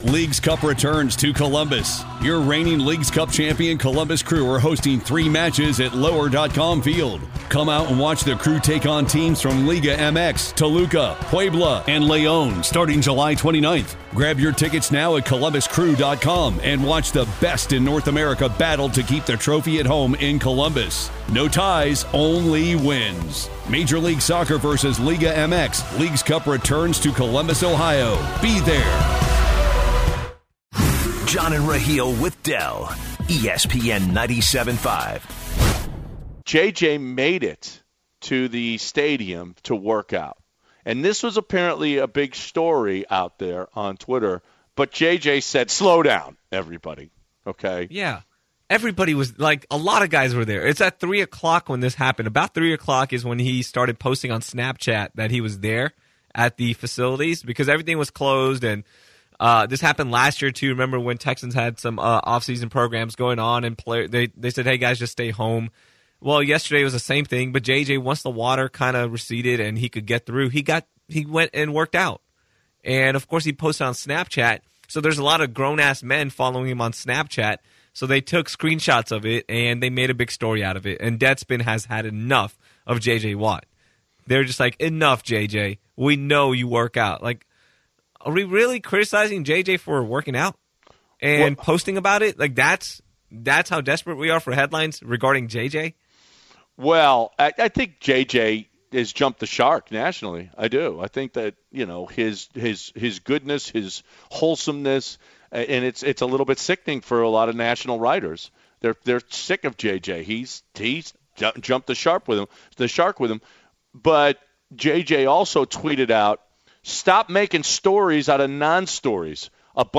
have a spirited debate over J.J. Watt's media presence. Is Watt in the wrong for being upset over the tabloids gossiping about his every move?